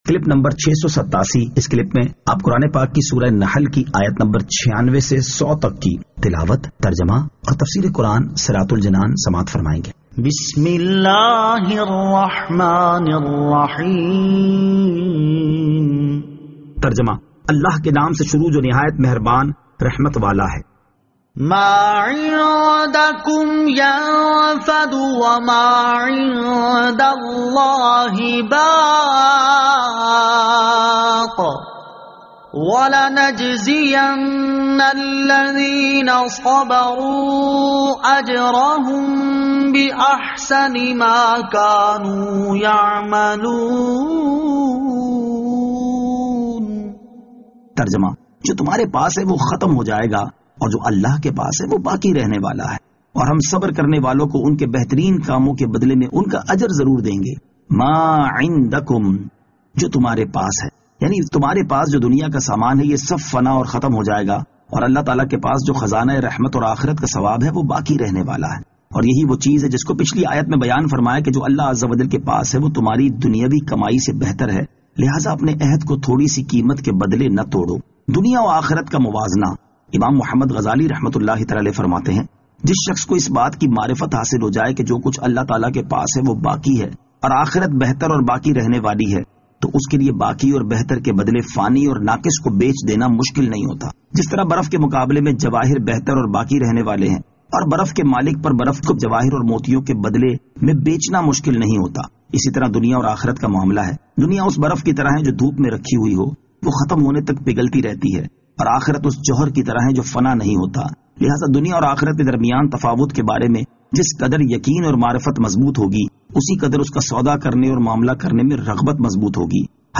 Surah An-Nahl Ayat 96 To 100 Tilawat , Tarjama , Tafseer